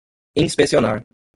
Ausgesprochen als (IPA)
/ĩs.pe.si.oˈna(ʁ)/